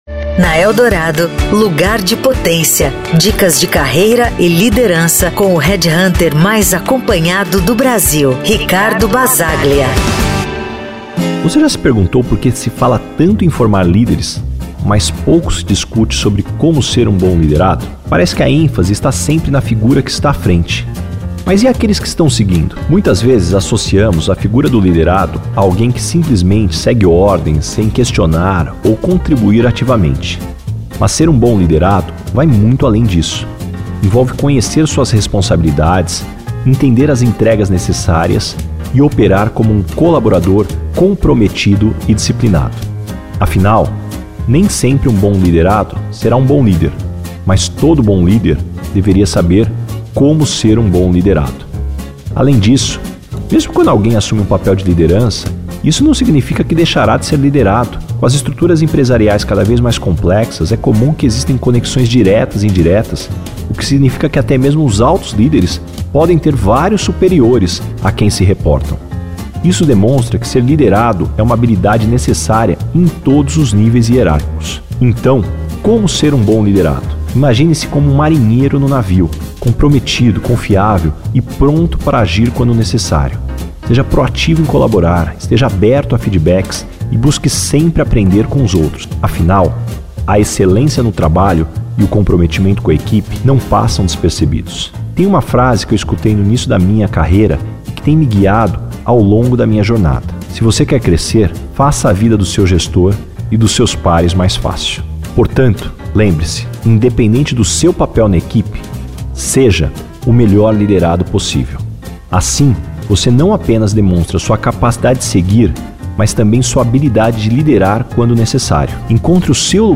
Categoria: Coluna